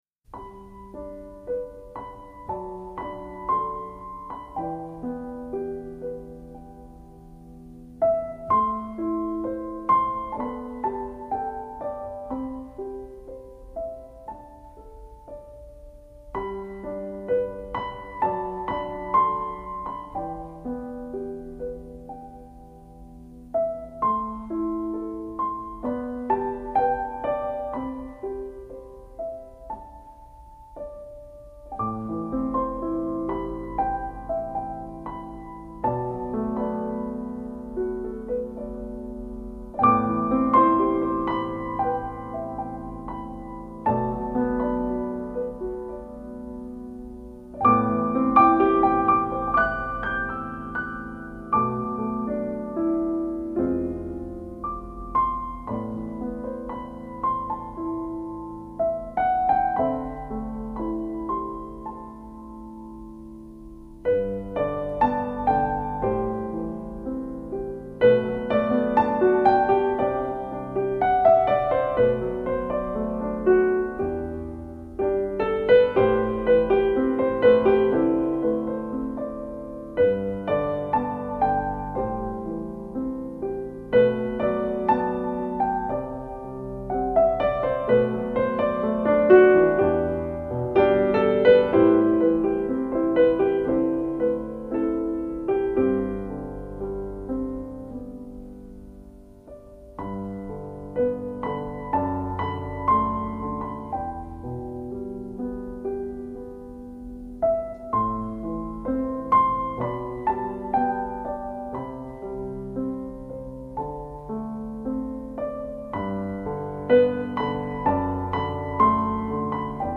主要演奏器乐： 钢琴
乐风： 新世纪
在他的音乐作品裡，融合了东方抒情与西方典雅的音乐风格。